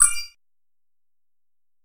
Чуть заметный